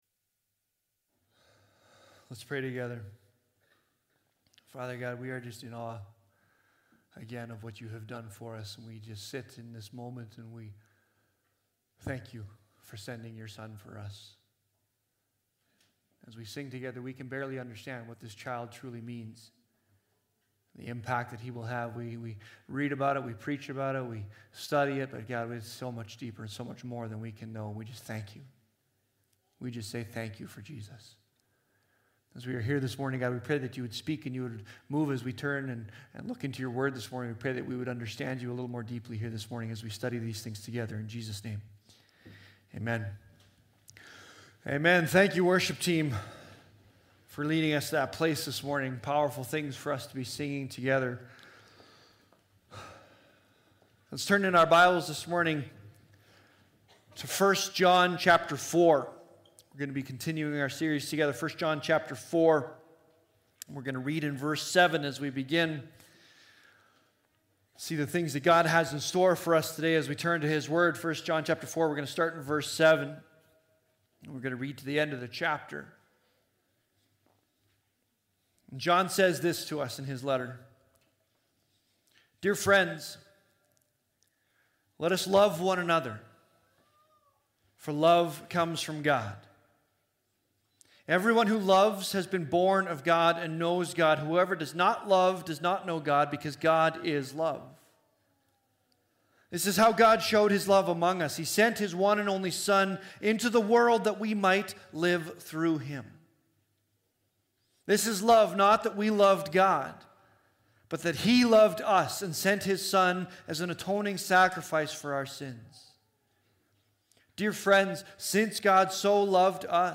Sermons | Leduc Fellowship Church